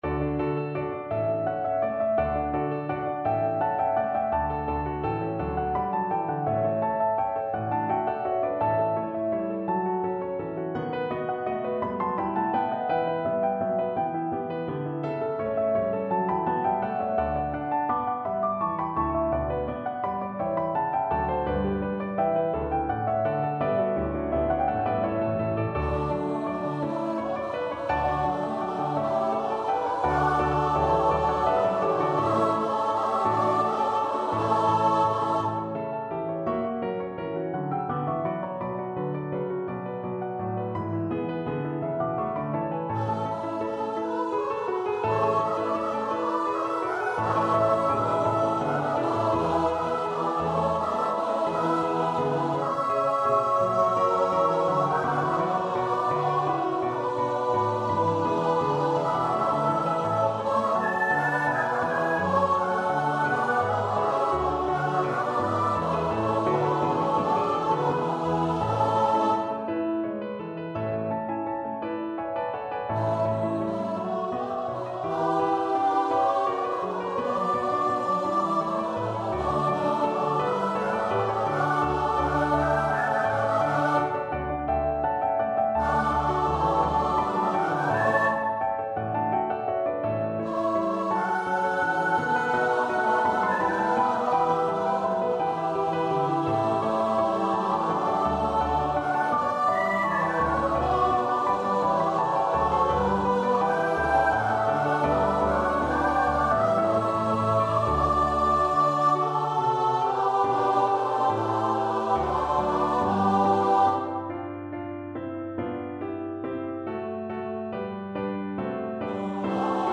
Free Sheet music for Choir (SSATB)
Choir  (View more Intermediate Choir Music)
Classical (View more Classical Choir Music)